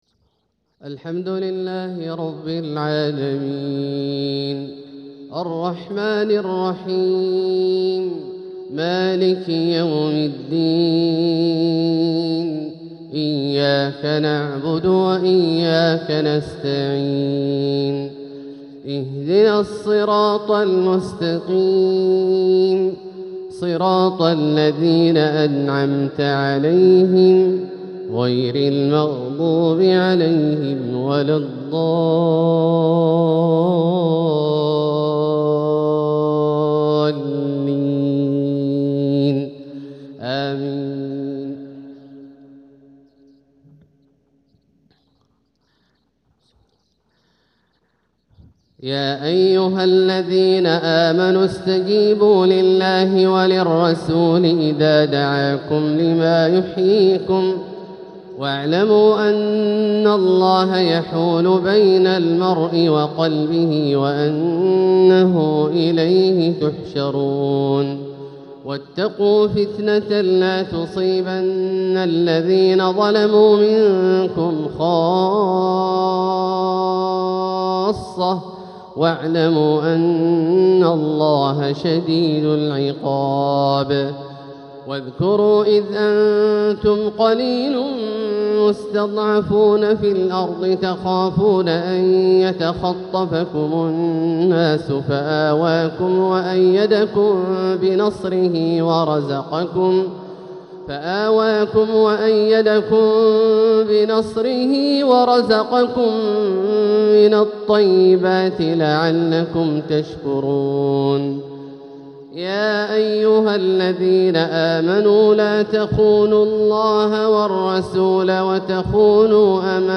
تلاوة من سورة الأنفال | فجر الثلاثاء 4 صفر 1447هـ > ١٤٤٧هـ > الفروض - تلاوات عبدالله الجهني